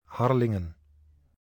Harlingen (Dutch: [ˈɦɑrlɪŋə(n)]
449_Harlingen.ogg.mp3